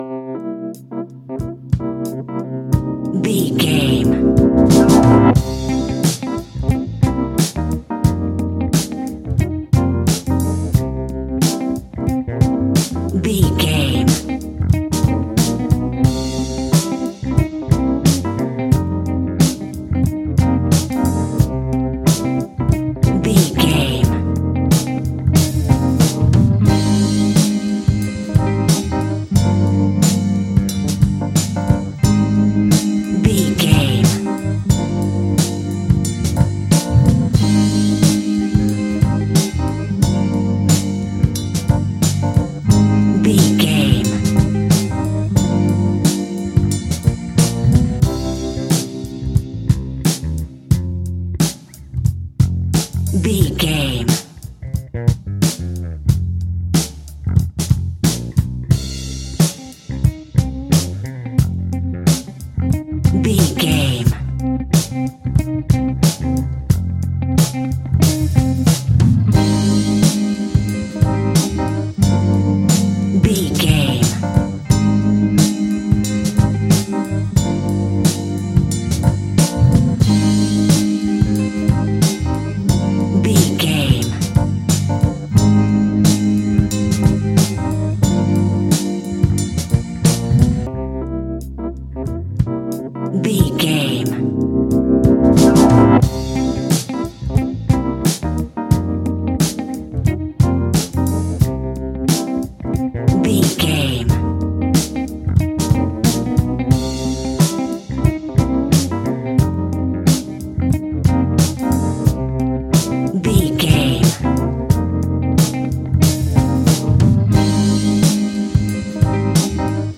Aeolian/Minor
funky
uplifting
bass guitar
electric guitar
organ
saxophone
groovy